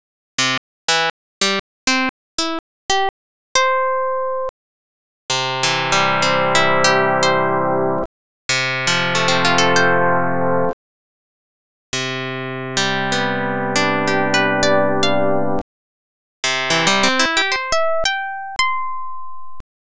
, however, a discerning ear can still detect some problems.
ksdemo_44kHz.wav